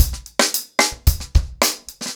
TimeToRun-110BPM.25.wav